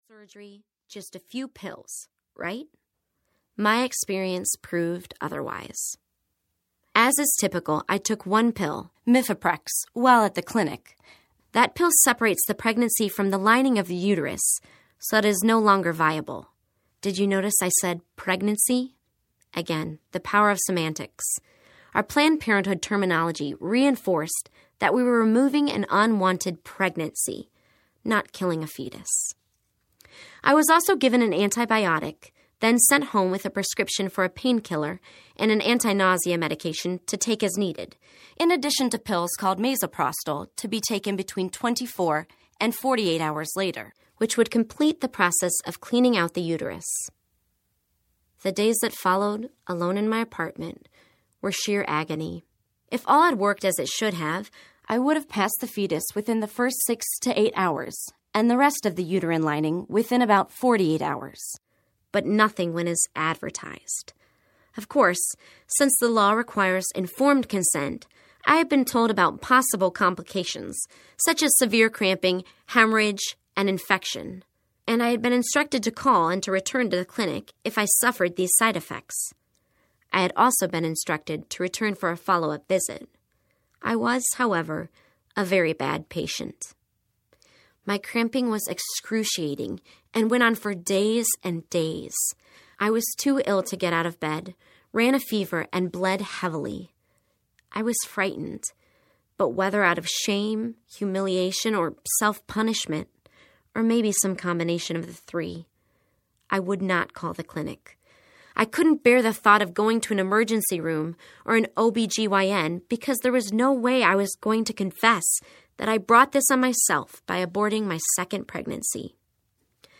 Unplanned TY Audiobook